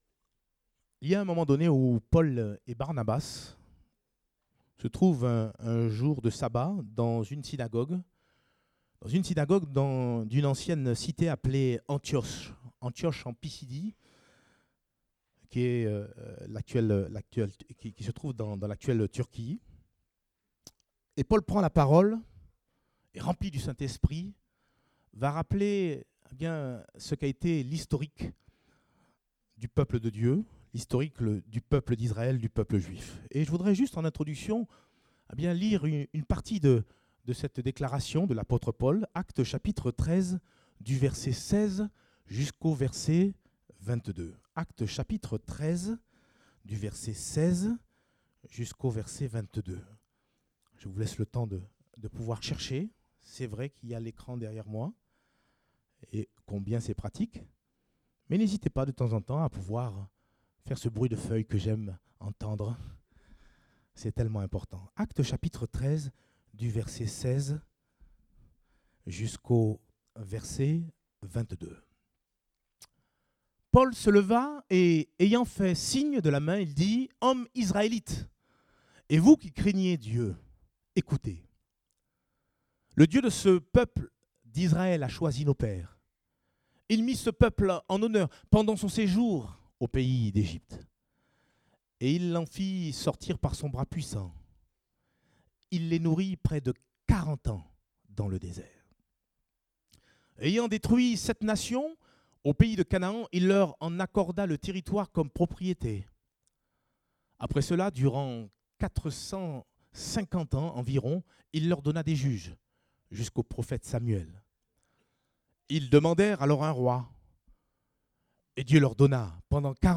Type De Service: Culte Dominical